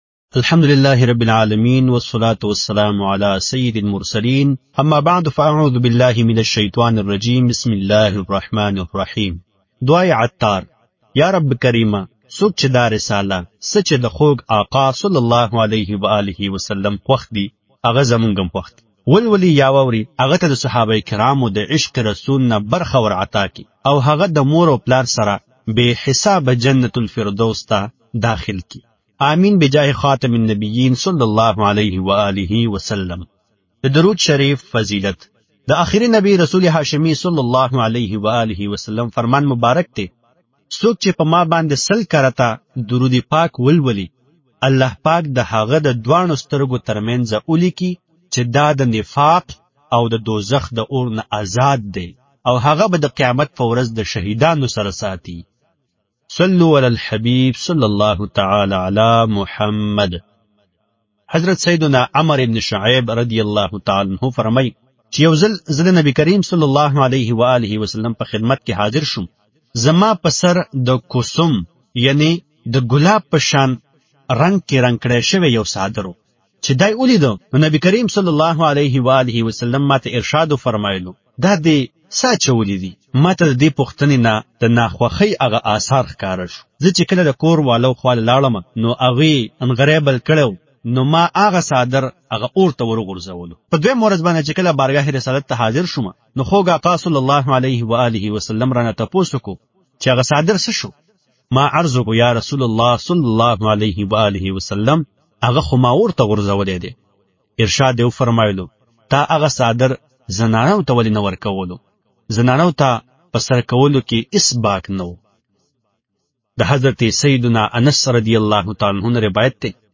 Audiobook - Jo Aaqa صلی اللہ تعالی علیہ وسلم Ki Pasand Wo Apni Pasand (Pashto)